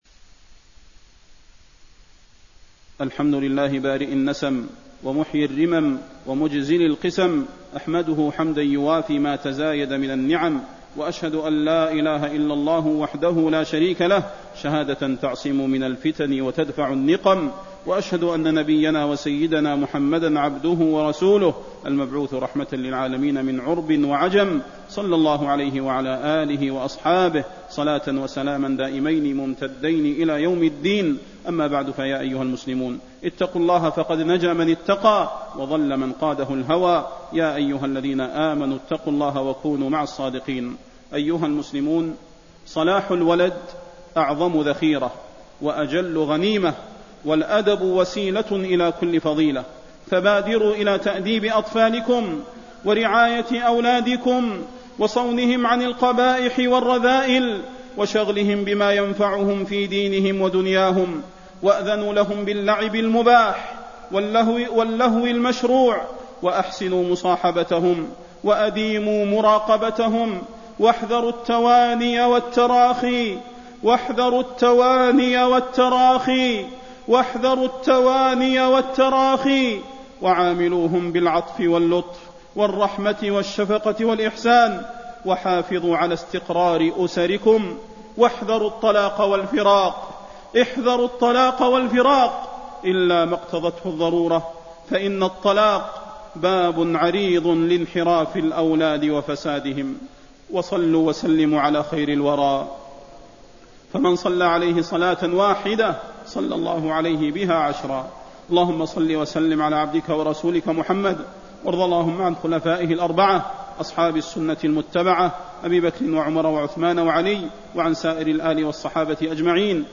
فضيلة الشيخ د. صلاح بن محمد البدير
تاريخ النشر ١٨ رجب ١٤٣٣ هـ المكان: المسجد النبوي الشيخ: فضيلة الشيخ د. صلاح بن محمد البدير فضيلة الشيخ د. صلاح بن محمد البدير الشباب والفراغ والإجازة